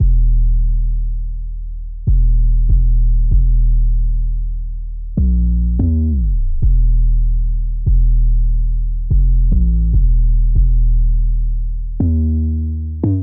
藐视这个 808 145bpm
描述：陷阱和科幻的结合。沉重的打击和神秘感。使用这些循环来制作一个超出这个世界范围的爆炸性节目。）D小调
Tag: 145 bpm Trap Loops Bass Wobble Loops 2.23 MB wav Key : D